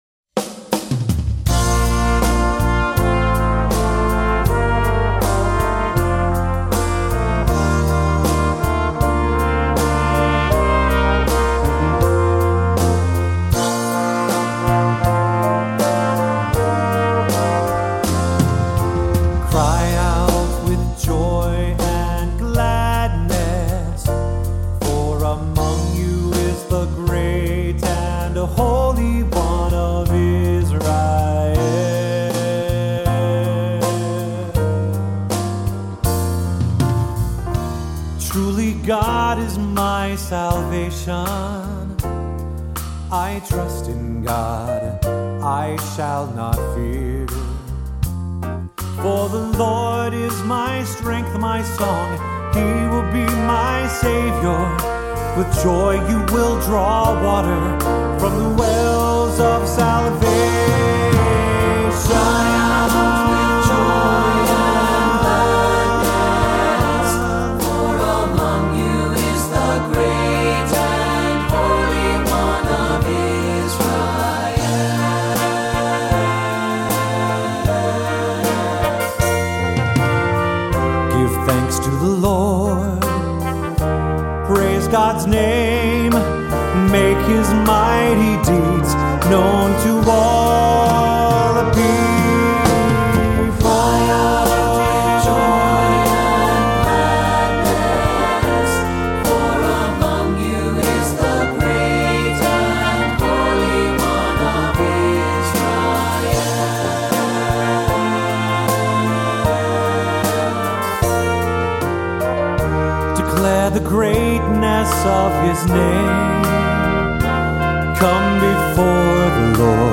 Voicing: Assembly, cantor